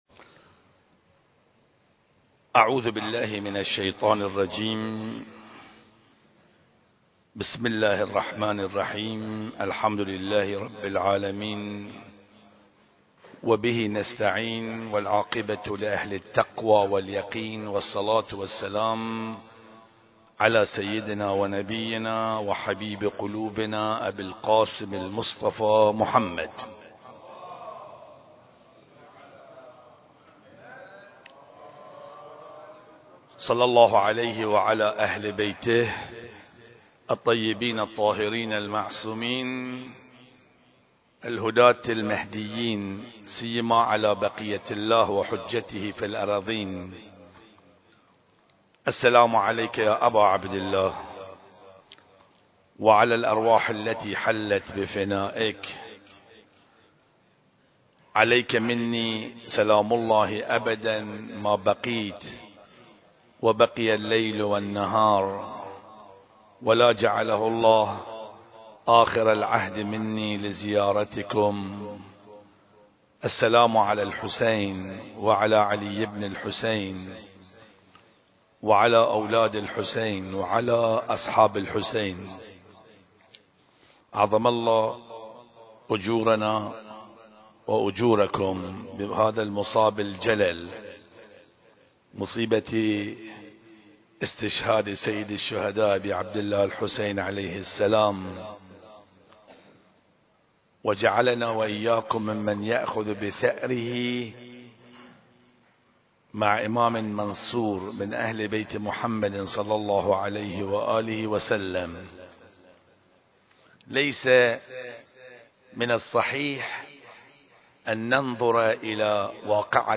المكان: مسجد الغدير - البحرين